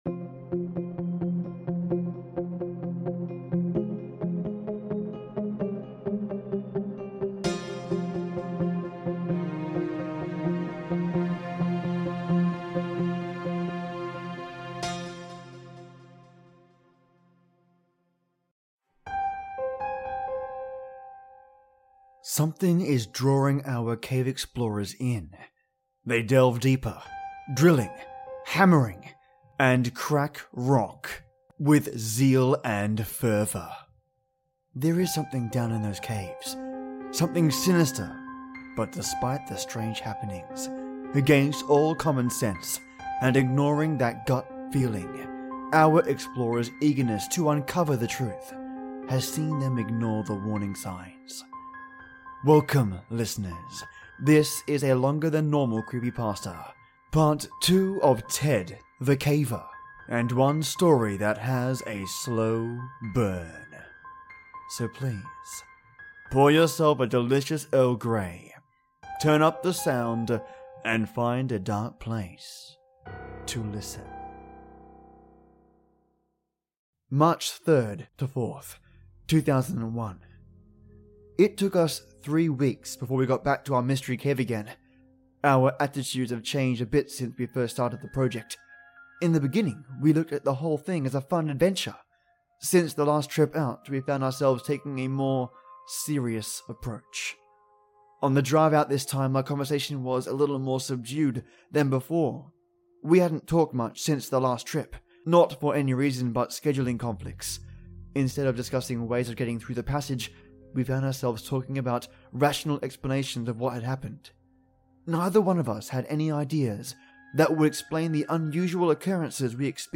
This is a longer than normal creepypasta, with a slow burn.